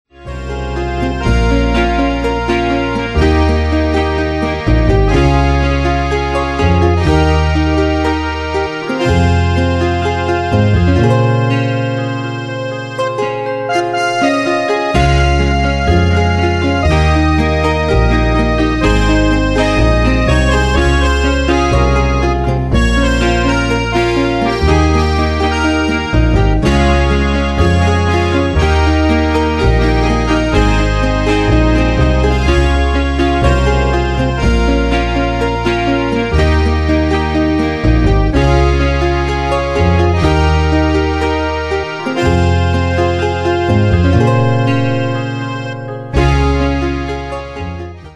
Demos Midi Audio
Danse/Dance: Ballade Cat Id.
Pro Backing Tracks